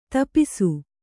♪ tapāsu